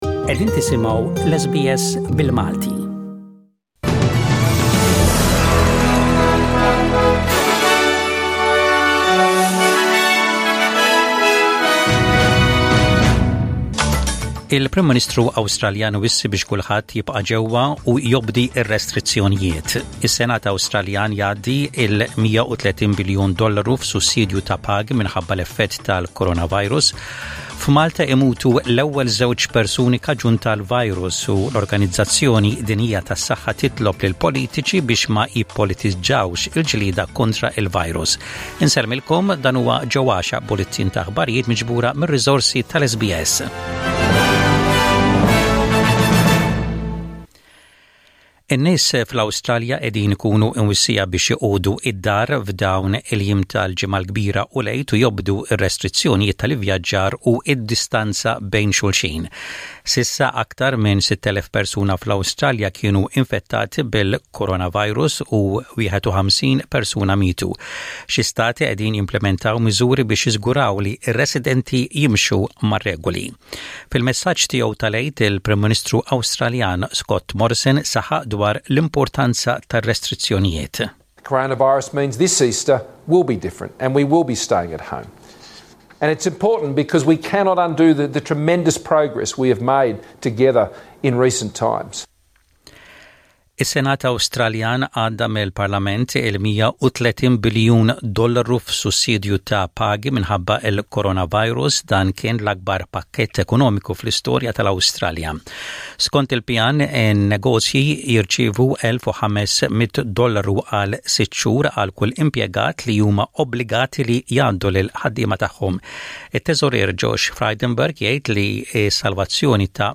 SBS Radio | Maltese News: 10/04/20